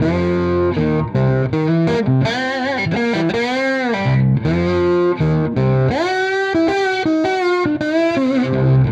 This is a collection of samples, not songs.